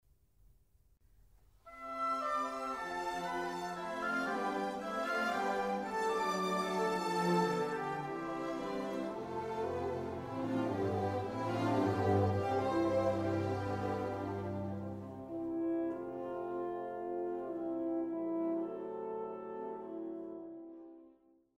C) 2nd mov.Coleridge -Taylor was inspired by Dvorak, and this influence can be heard in the pentatonic melody of the second movement.:
Example 4 – Opening of second movement: